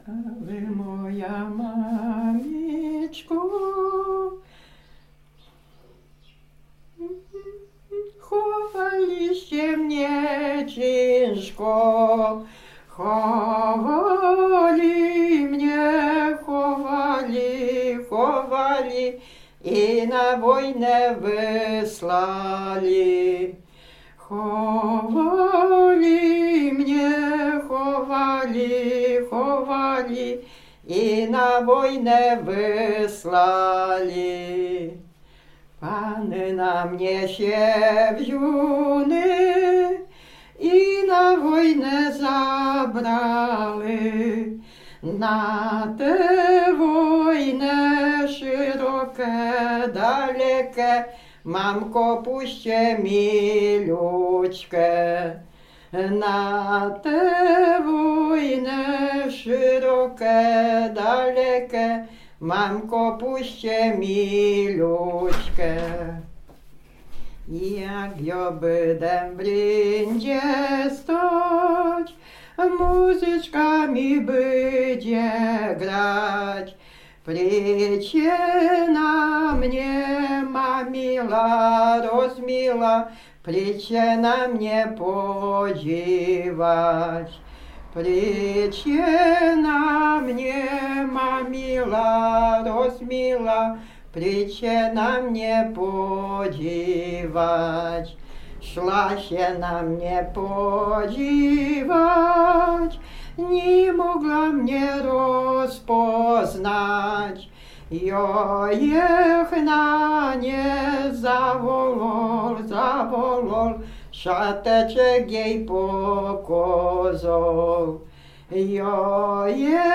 Lubuskie, powiat żagański, gmina Brzeźnica, wieś Wichów